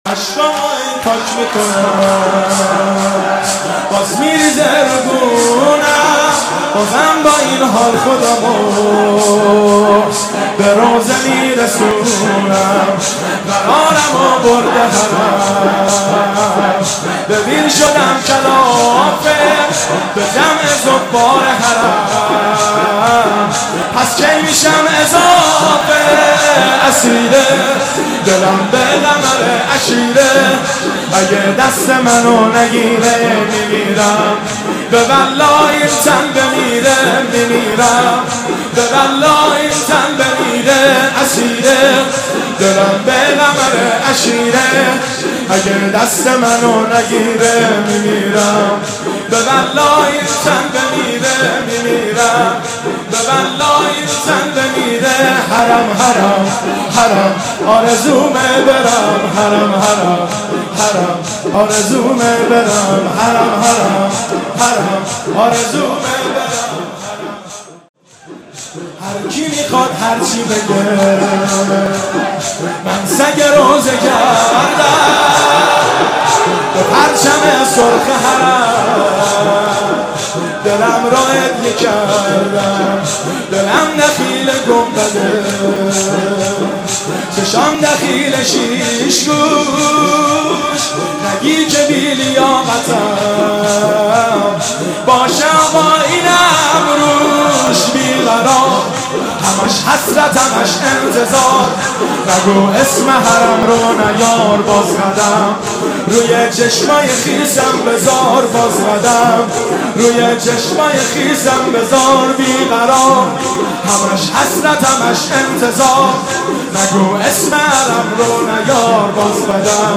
مداحی اشکهامو هی پاک میکنم(شور)
شب اول محرم 1392
هیئت خادم الرضا (ع) قم